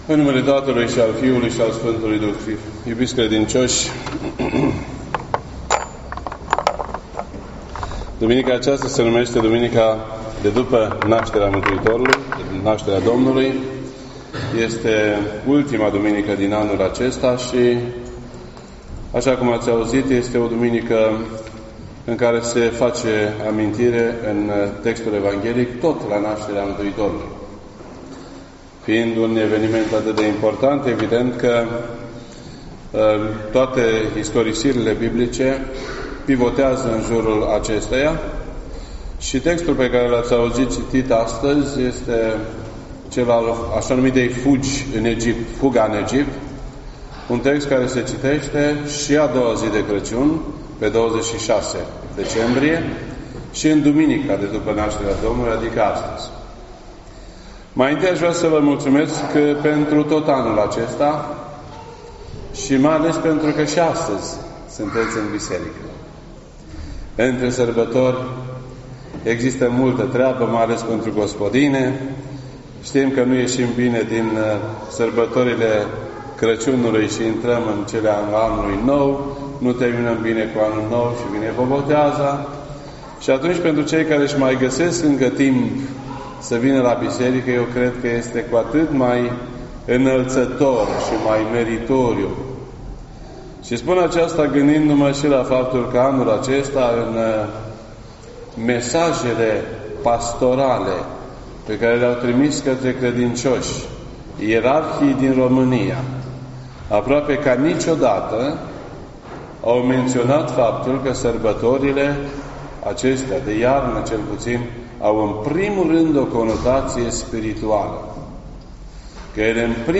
This entry was posted on Sunday, December 30th, 2018 at 12:39 PM and is filed under Predici ortodoxe in format audio.